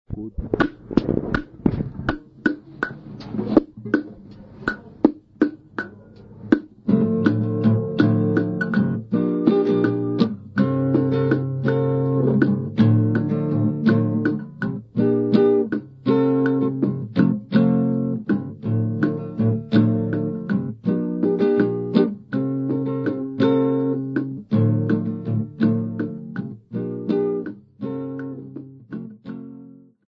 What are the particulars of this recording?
Africa South Africa Grahamstown f-sa field recordings Cassette tape